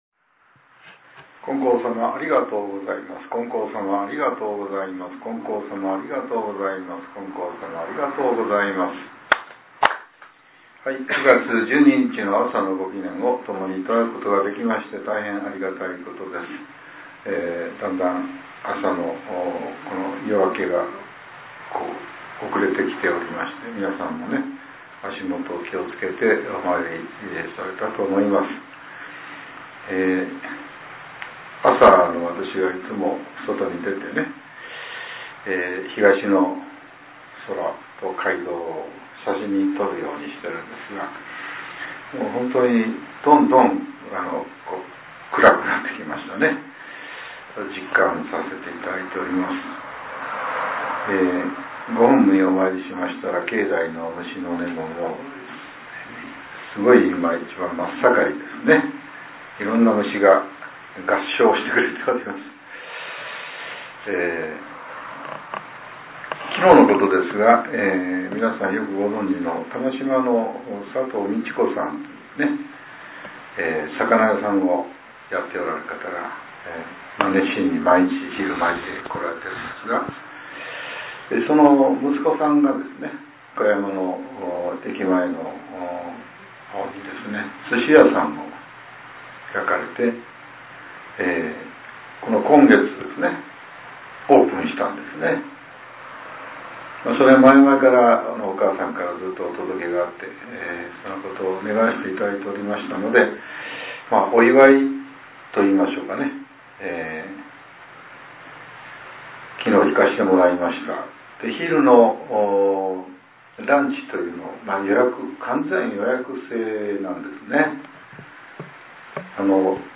令和７年９月１２日（朝）のお話が、音声ブログとして更新させれています。